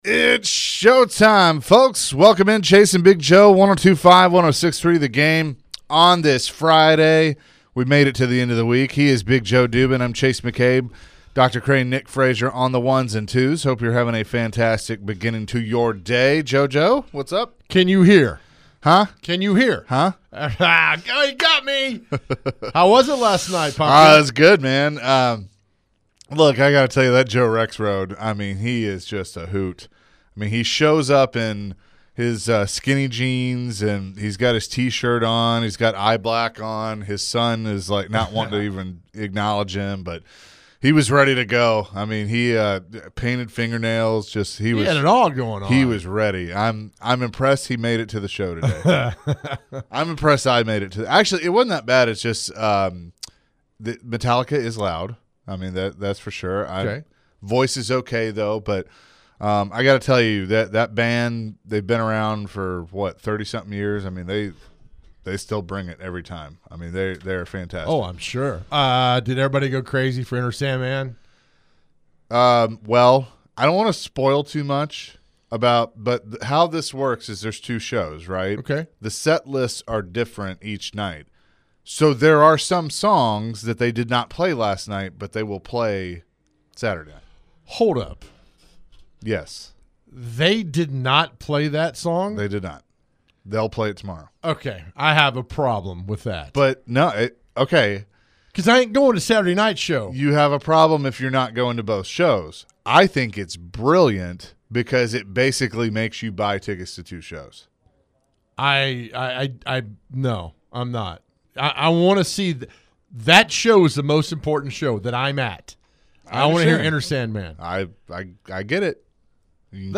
The guys ended the hour answering some calls and texts about football and the question of the day.